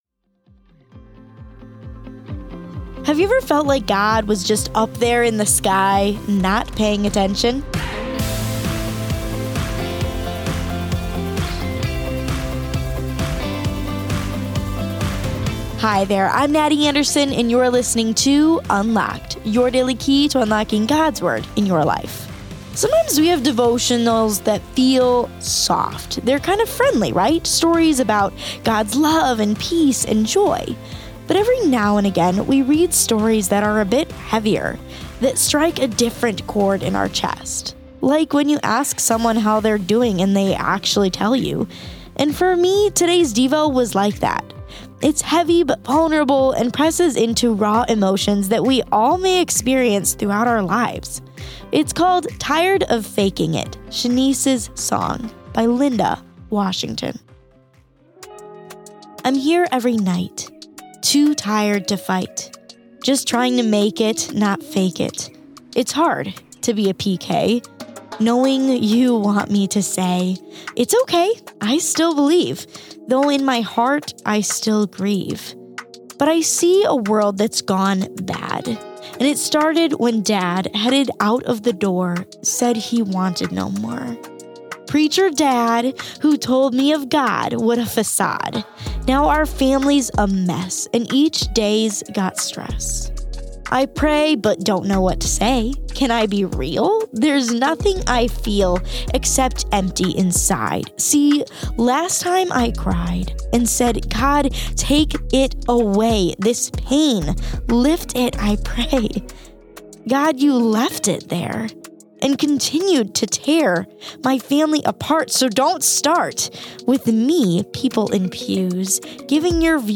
Today’s poem is written in the style of a rap.